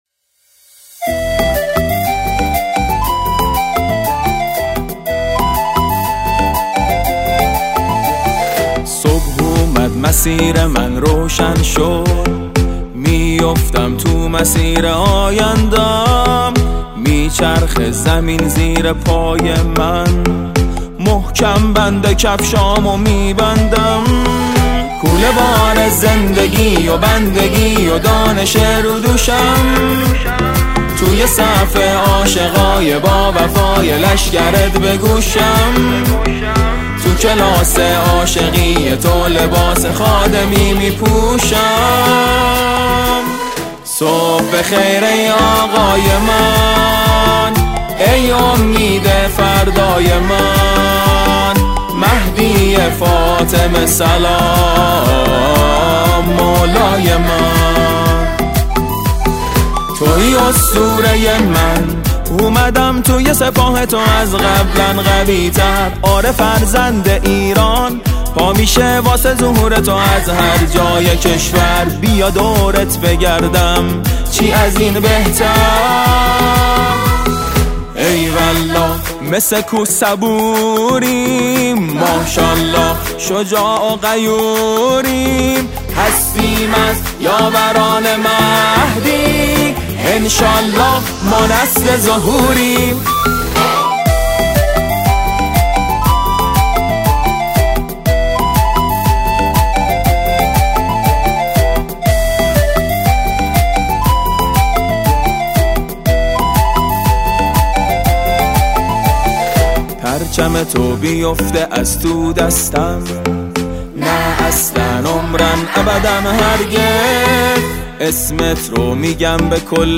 سرود ، سرود مذهبی ، سرود مناسبتی